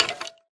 skeletonhurt2.ogg